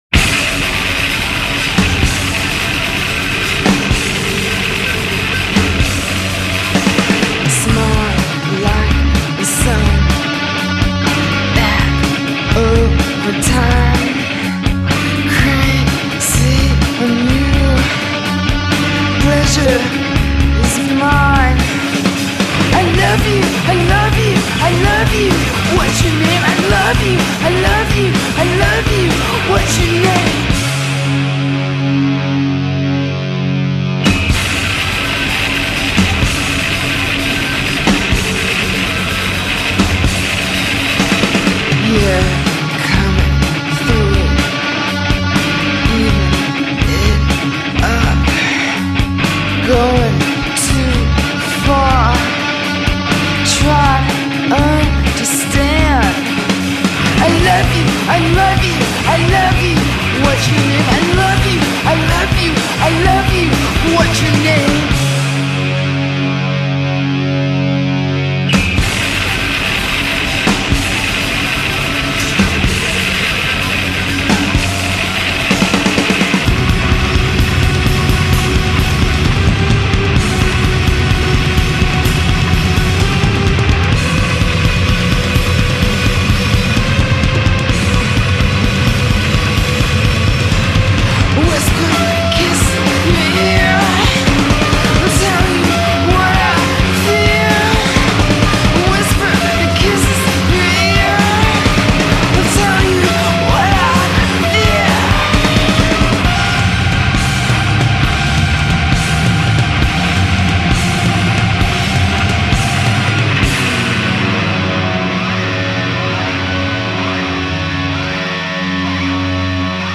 Жанр Alternative, Rock